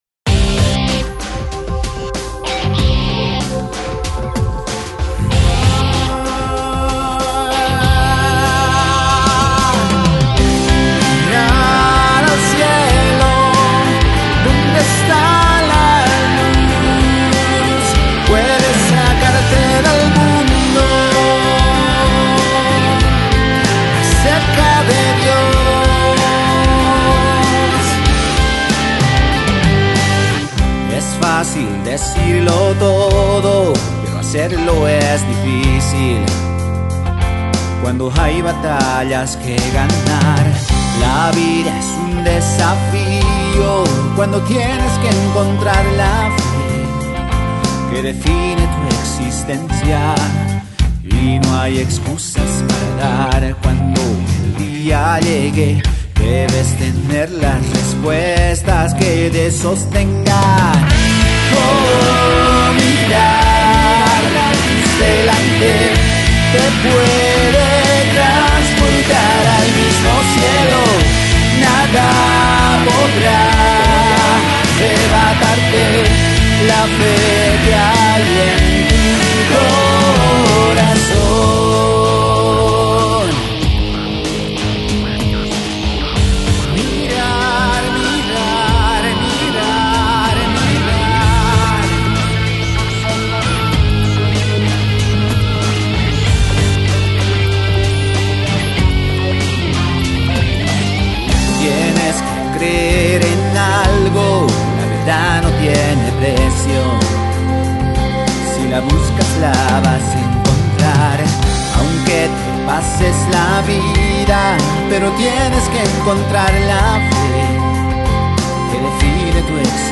Género: Rock/Pop/Alternativo